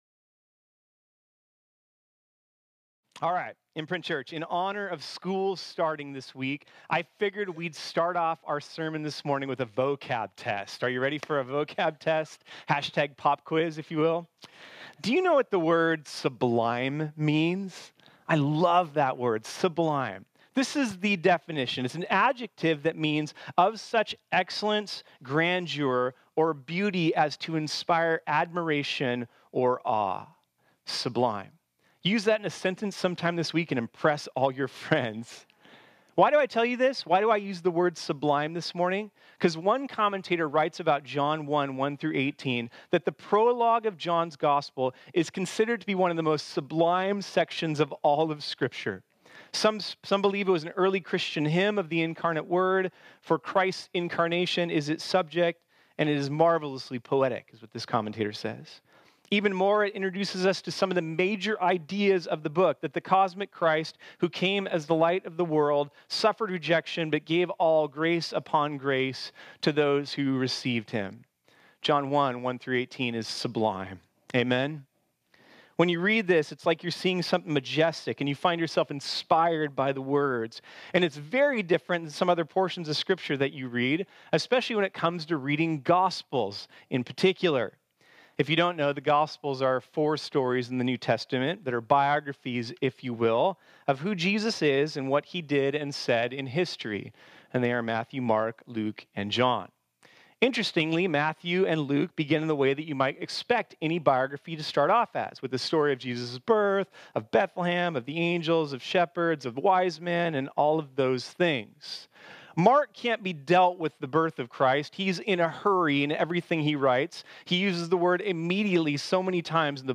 This sermon was originally preached on Sunday, September 8, 2019.